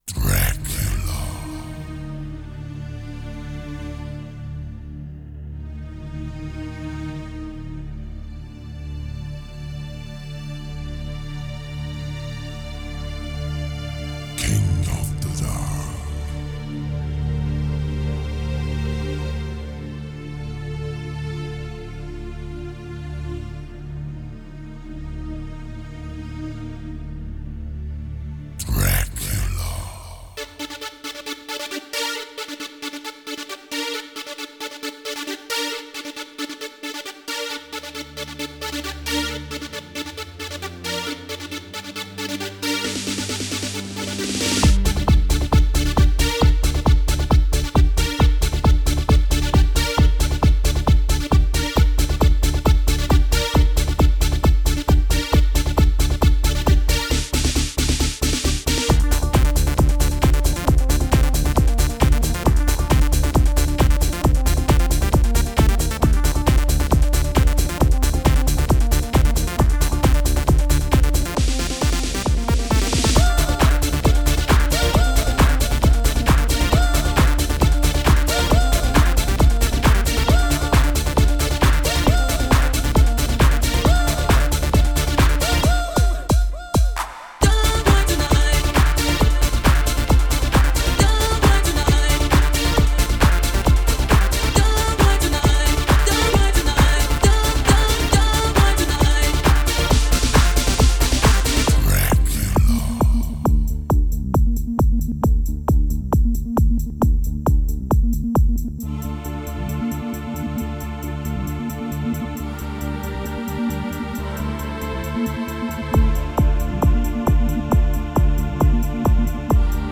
Genre: Euro-House.